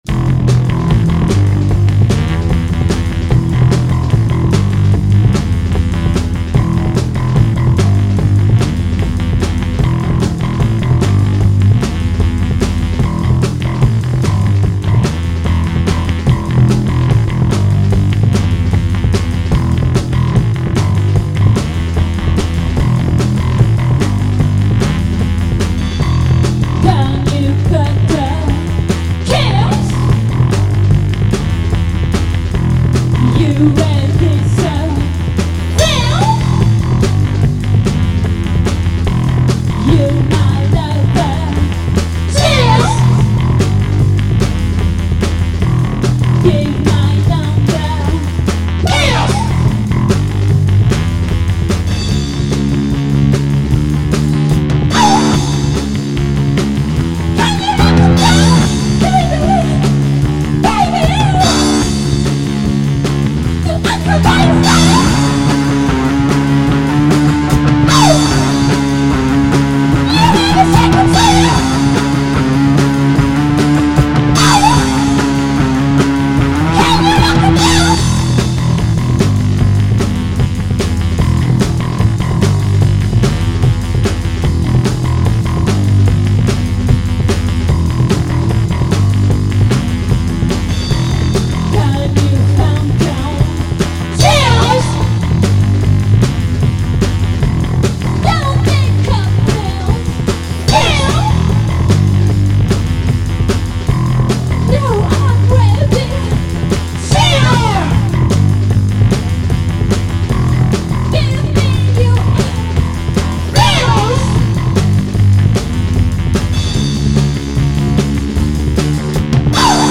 vocals
drums
both playing bass